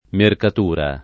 mercatura
[ merkat 2 ra ]